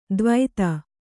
♪ dvaita